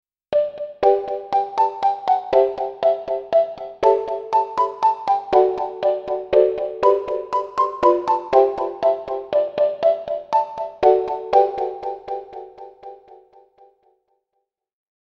Genres: Sound Effects